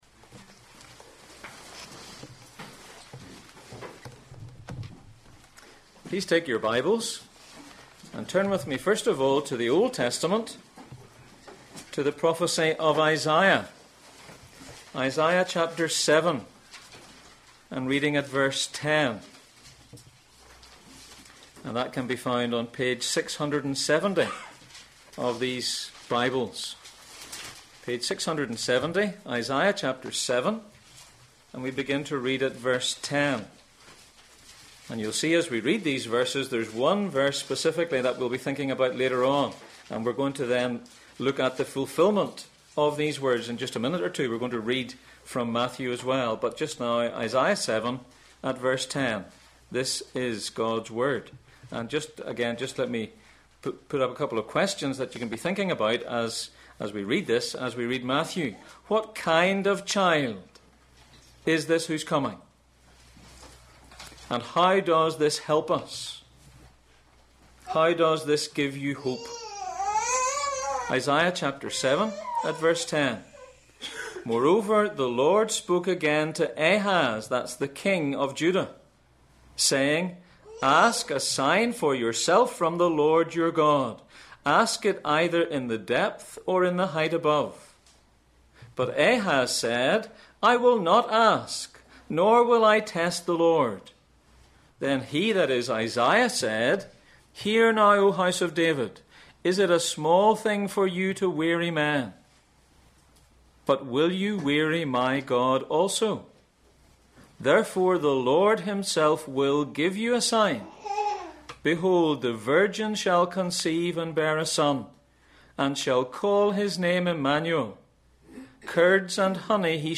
Passage: Isaiah 7:10-17, Matthew 1:18-25 Service Type: Sunday Morning %todo_render% « Why Cain’s sin was so serious Such Wise Men!